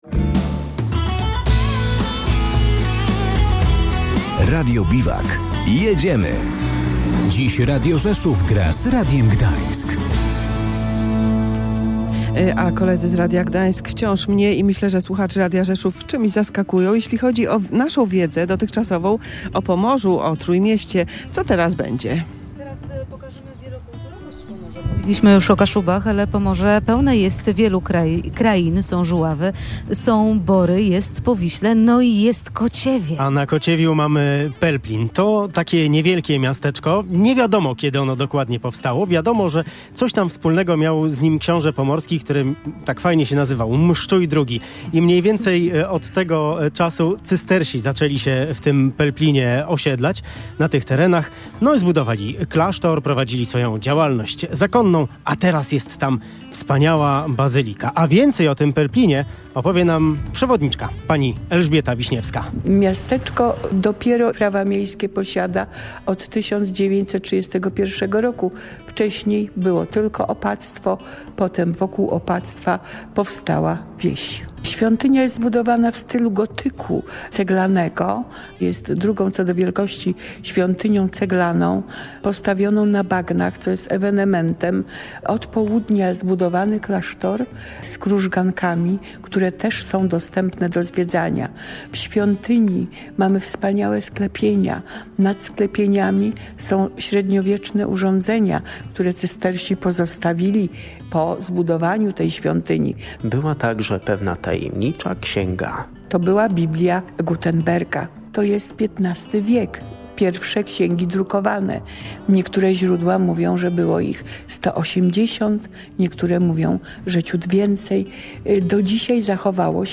W czwartek przed południem plenerowe studio Radia Gdańsk stanęło na końcu Skweru Kościuszki, tuż obok Akwarium w Gdyni. I tam, ze swoim specjalnym wozem, w ramach akcji „Radio Biwak” odwiedzili nas prezenterzy i reporterzy z zaprzyjaźnionego Radia Rzeszów.
Rzeszowska rozgłośnia realizuje cykl wakacyjnych audycji, jeżdżąc po całej Polsce i nadając na żywo z różnych zakątków naszego kraju.